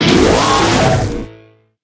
Cri de Salarsen dans sa forme Aigüe dans Pokémon HOME.
Cri_0849_Aigüe_HOME.ogg